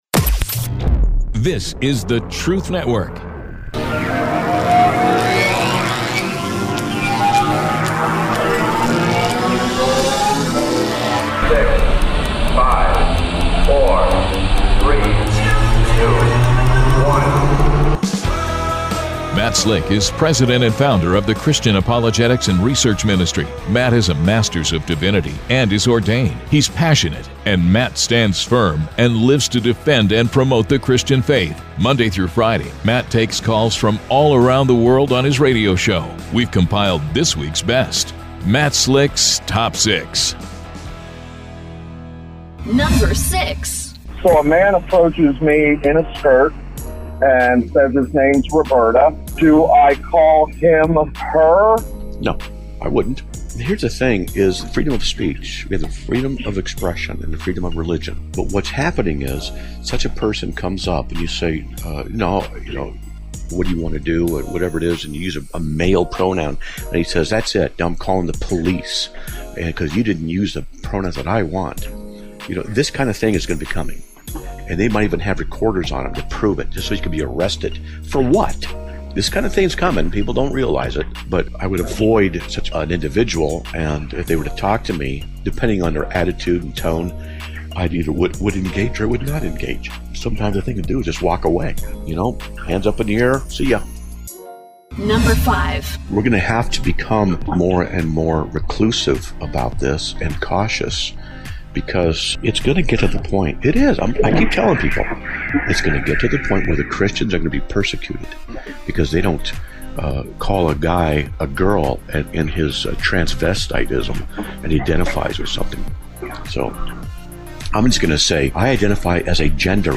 The sound bites are from Roy Rogers